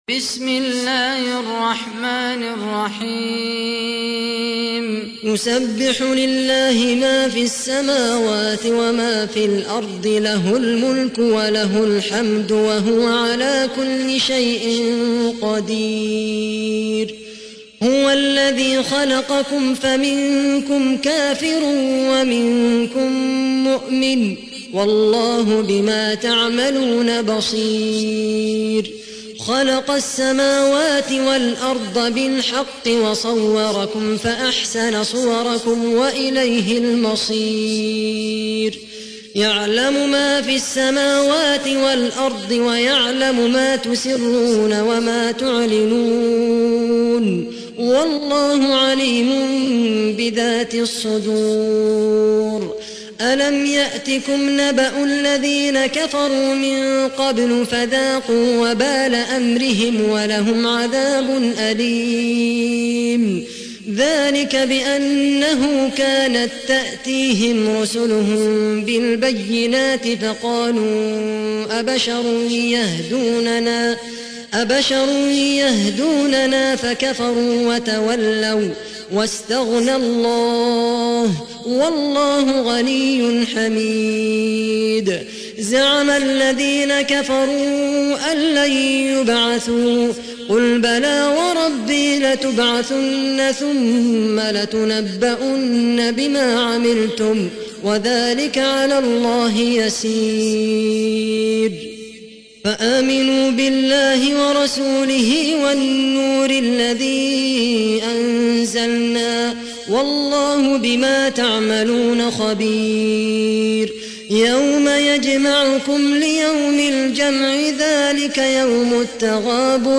تحميل : 64. سورة التغابن / القارئ خالد القحطاني / القرآن الكريم / موقع يا حسين